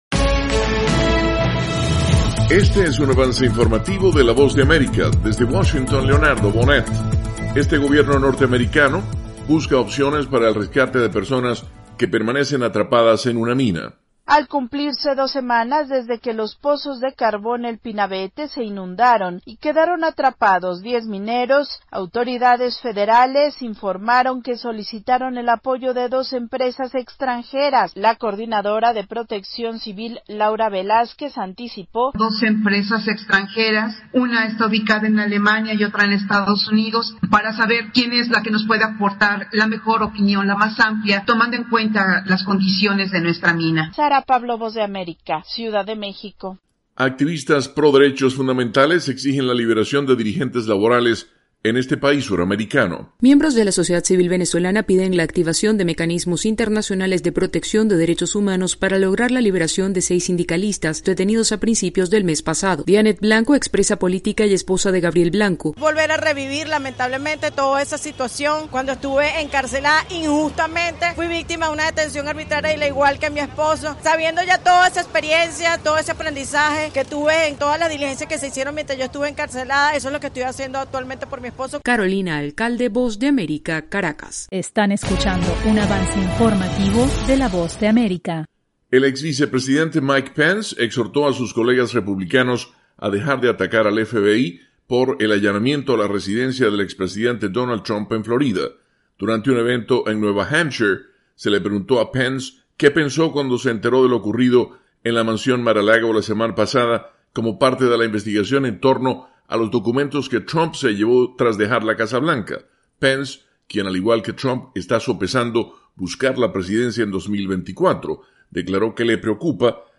Avance Informativo
El siguiente es un avance informativo presentado por la Voz de América, desde Washington